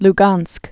(l-gänsk)